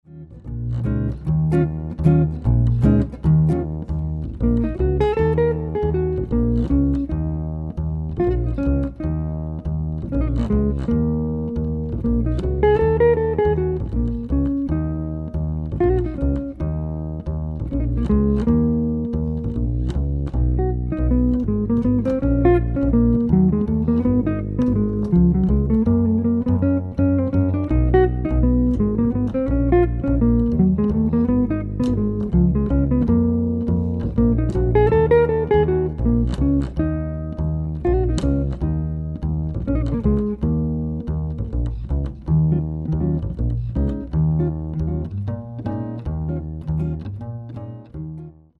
Cool-Jazz